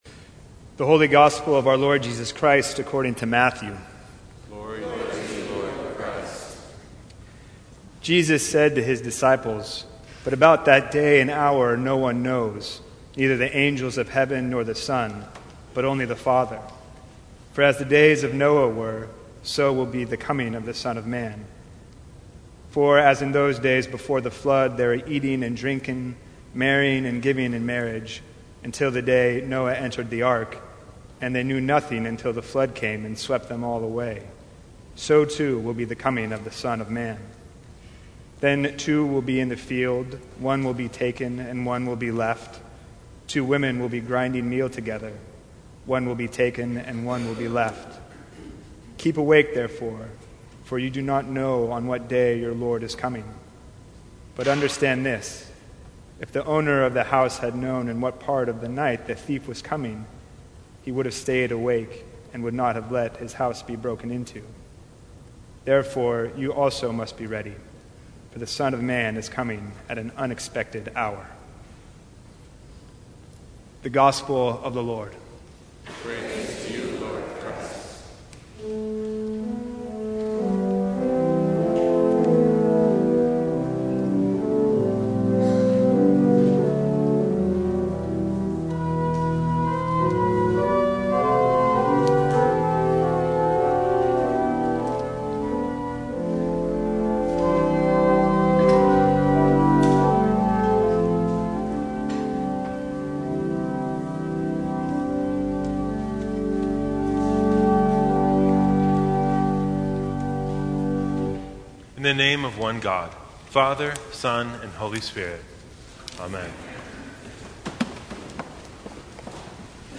First Sunday of Advent.
Sermons from St. Cross Episcopal Church You Are the House of God Dec 02 2019 | 00:10:24 1x 00:00 / 00:10:24 Subscribe Share Apple Podcasts Spotify Overcast RSS Feed Share Link Embed